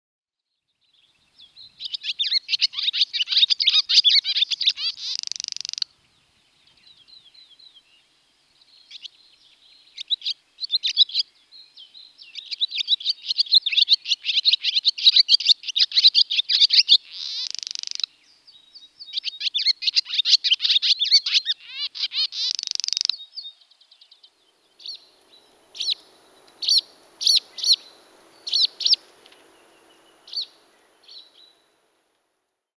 Kuuntele: Haarapääsky tuo kesän